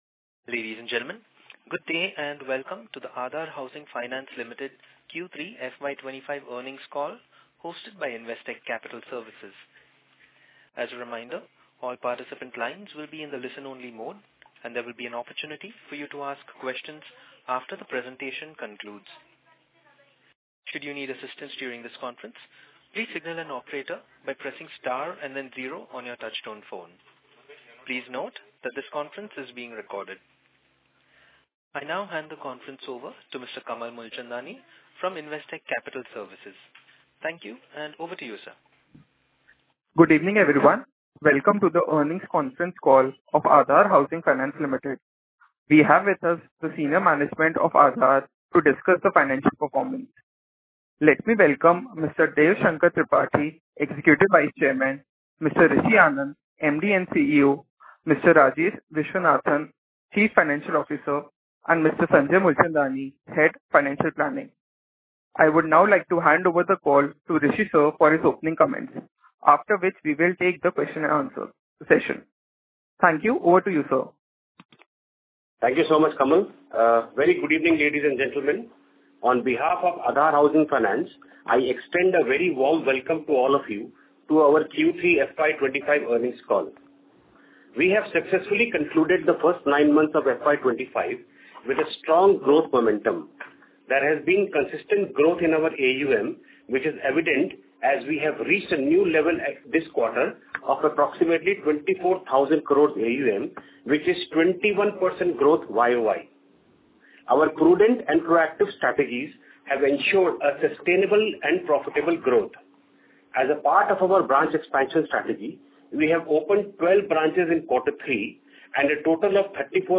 Earnings Call Invite -- Investor Presentation -- Audio Recordings of Earning Conference Call for the Quarter - FY 25-26